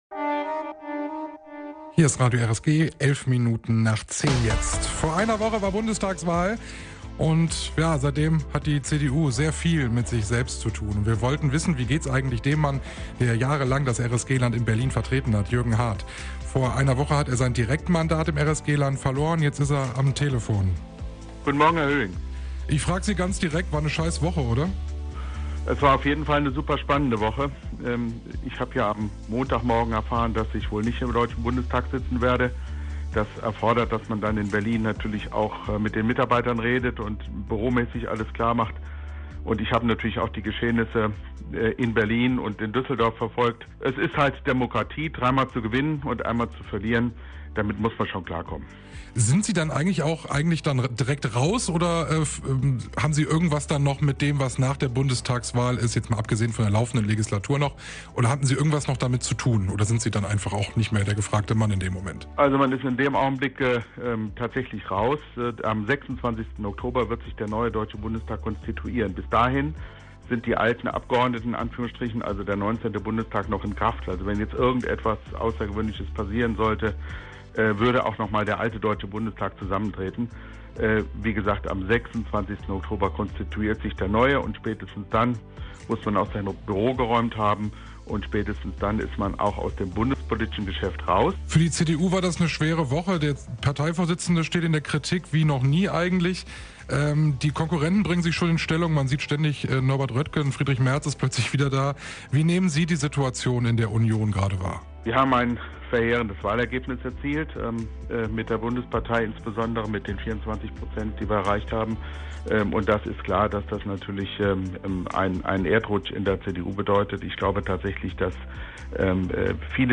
Eine Woche nach der verlorenen Bundestagswahl spricht der CDU-Politiker im RSG-Interview über seine Zukunft.